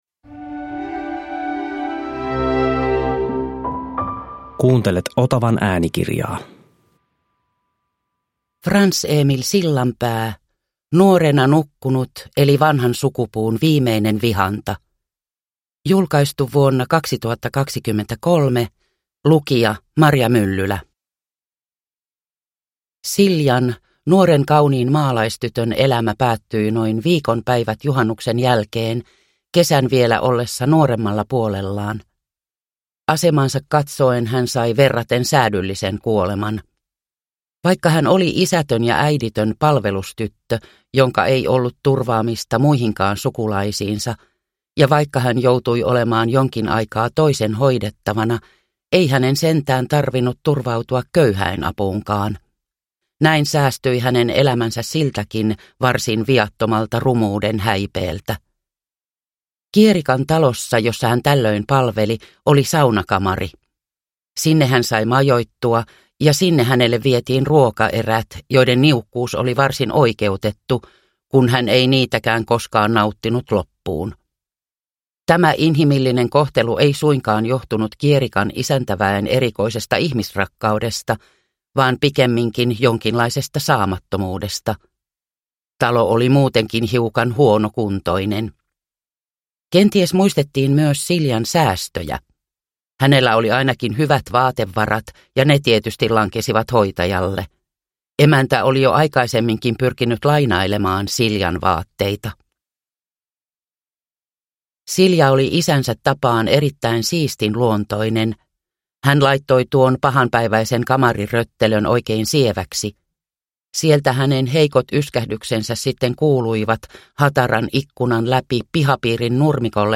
Nuorena nukkunut – Ljudbok – Laddas ner